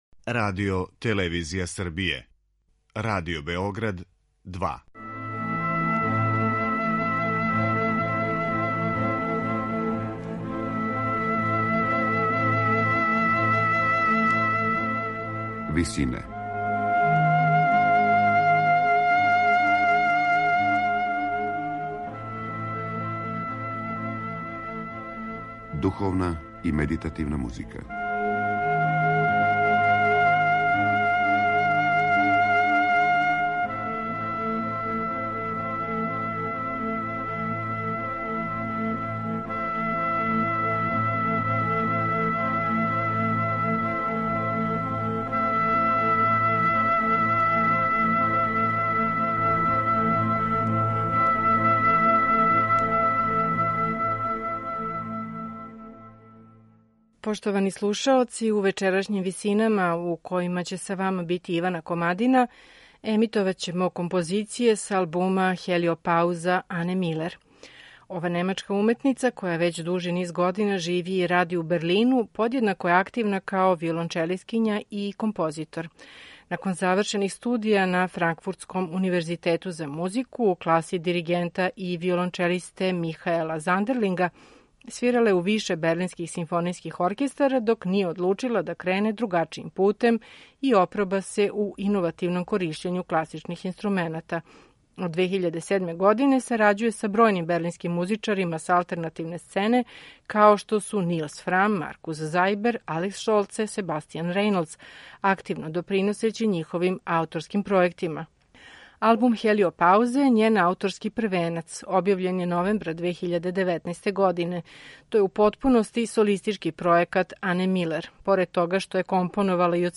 Берлинска виолончелисткиња и композиторка